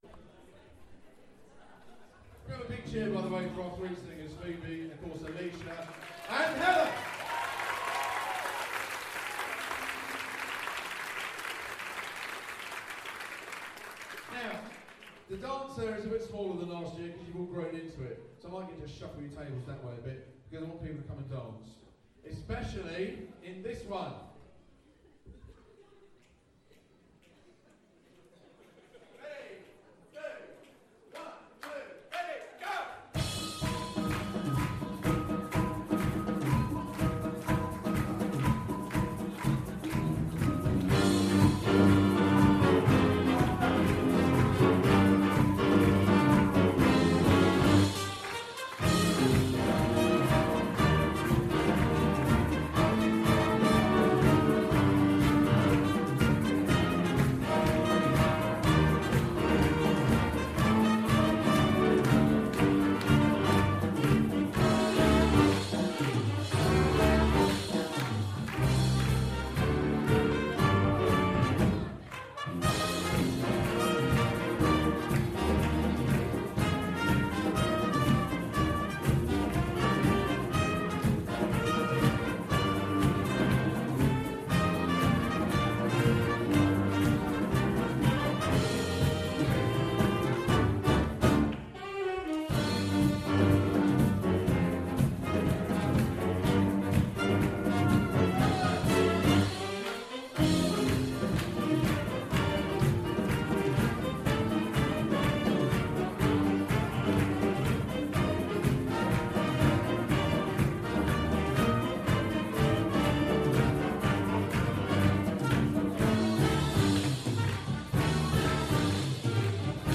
Big Band Evening 2018 1st Half